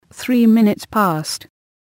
こちらは つづりを入力すると、その通りに話してくれるページです。
そこでAPR9600のAnalogInputにPCのLINEOUTを接続したところ、 適度な音量で再生ができるレベルになりました。